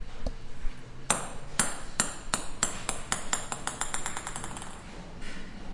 乒乓球
描述：乒乓球弹跳